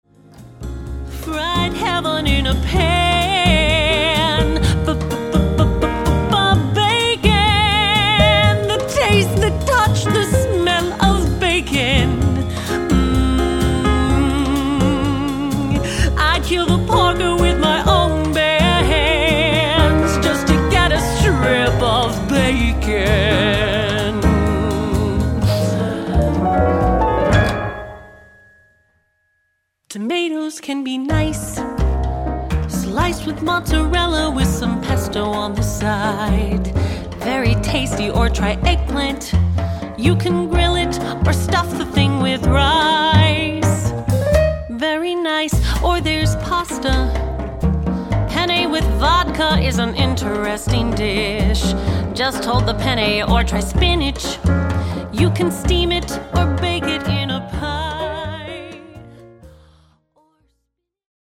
Broadway